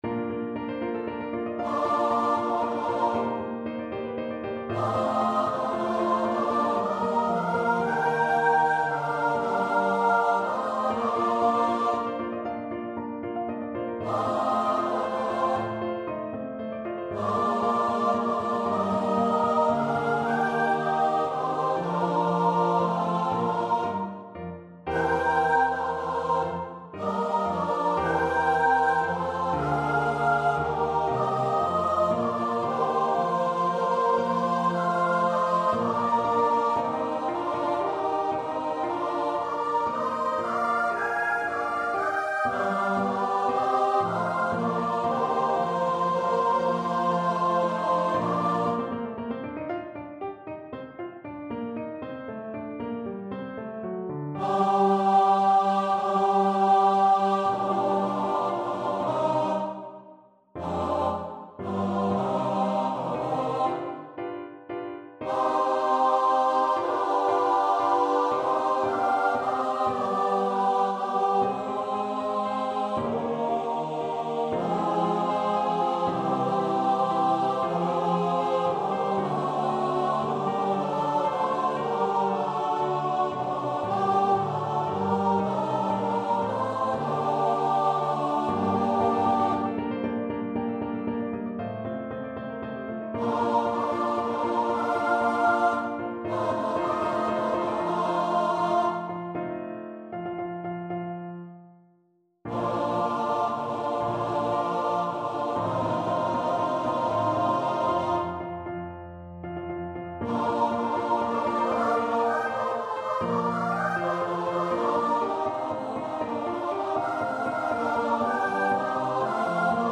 Instrument: Choir
Style: Classical